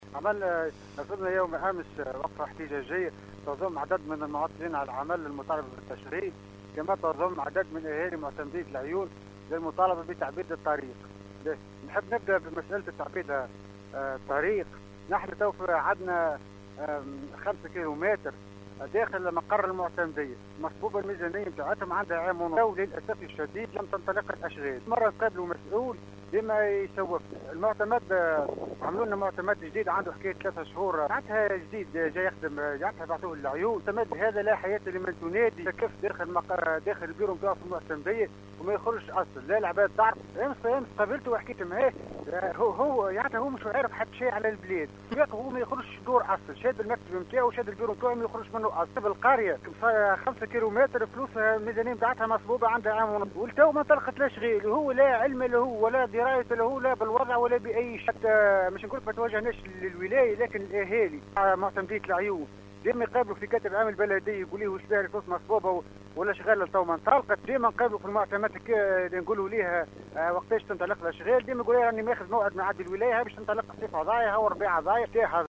مواطن من العيون.